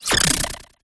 sinistea_ambient.ogg